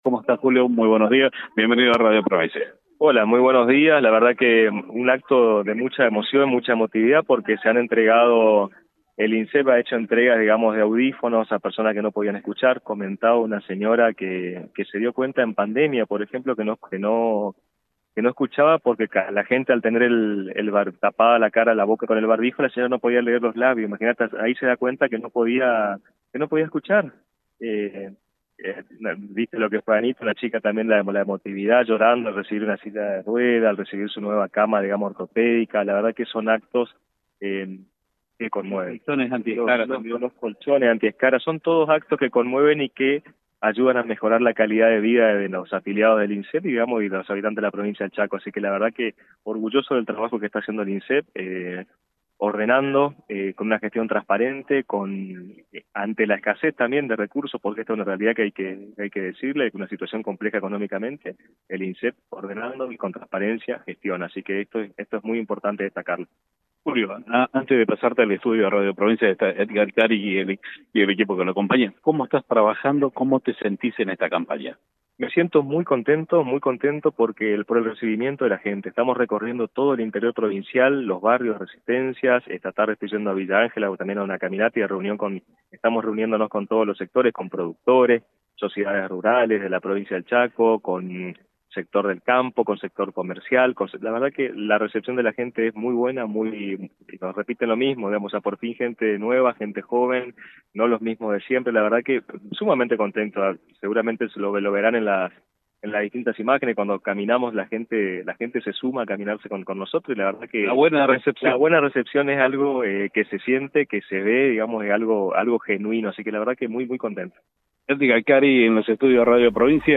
Durante la entrevista, valoró el trabajo del INSSSEP en la entrega de audífonos, sillas de ruedas y elementos ortopédicos a afiliados y personas con discapacidad.